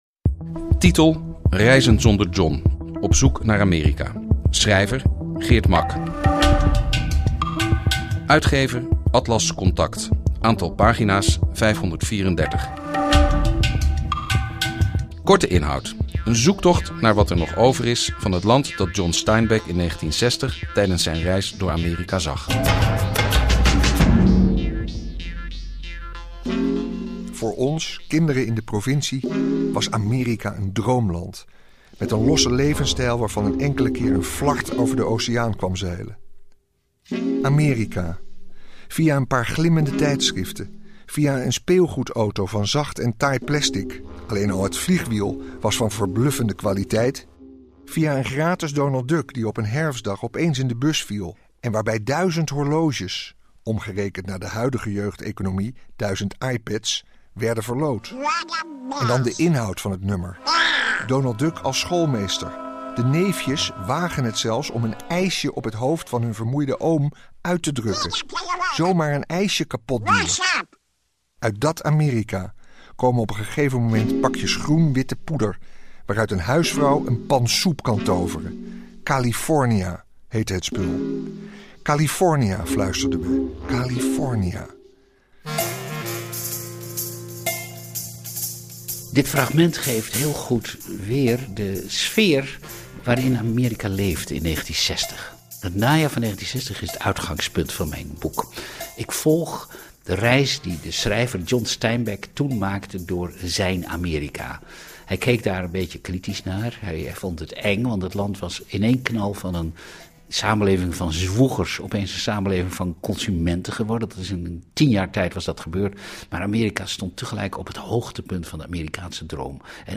Gesprekken met Geert Mak en Carolijn Visser in aanloop naar de uitreiking van de Bob den Uyl Prijs
De gesprekken werden uitgezonden in Bureau Buitenland van de VPRO-radio.